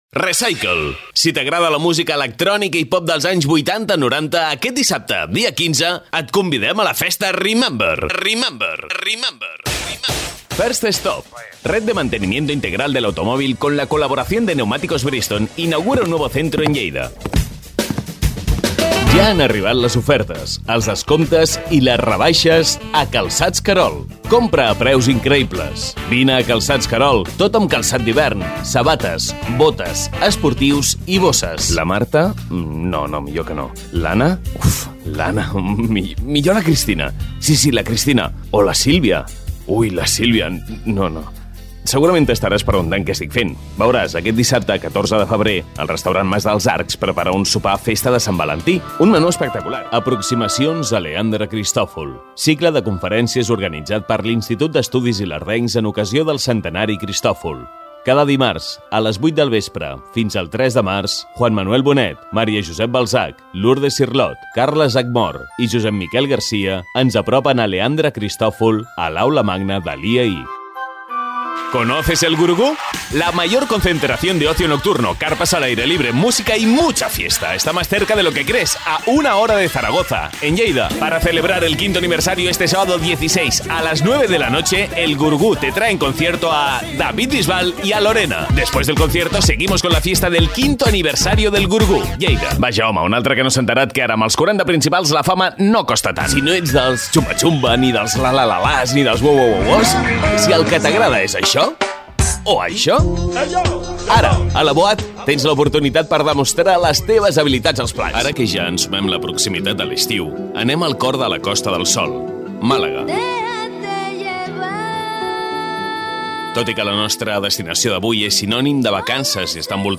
kastilisch
Sprechprobe: Werbung (Muttersprache):